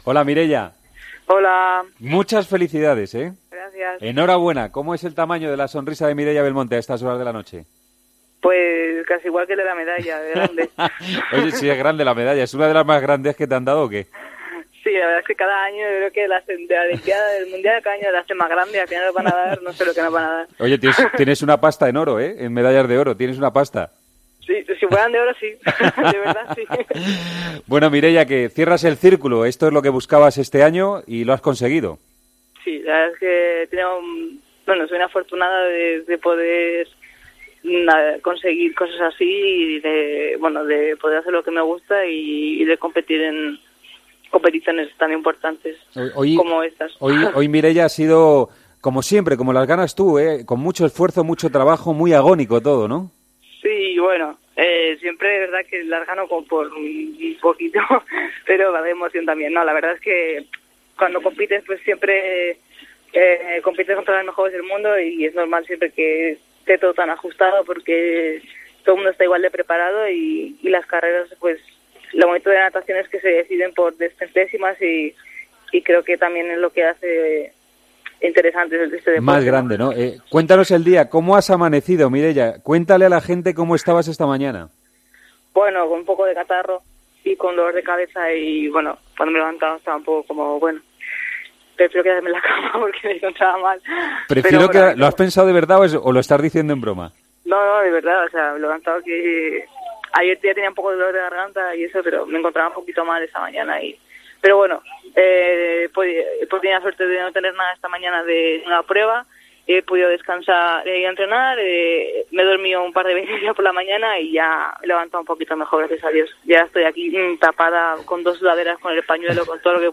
La nadadora española ha pasado por los micrófonos de El Partidazo de COPE después de conseguir la medalla de oro en el 200 mariposa en el Mundial de Budapest.